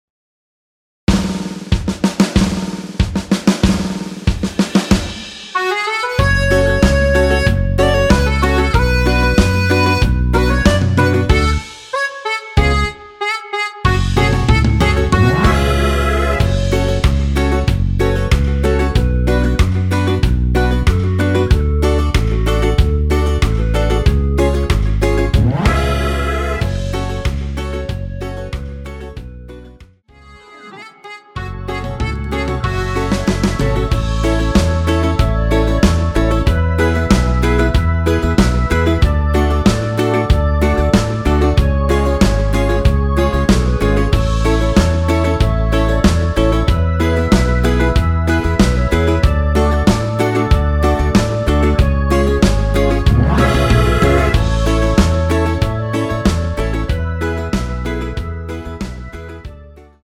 원키 멜로디 포함된 MR입니다.
F#m
멜로디 MR이라고 합니다.
앞부분30초, 뒷부분30초씩 편집해서 올려 드리고 있습니다.
중간에 음이 끈어지고 다시 나오는 이유는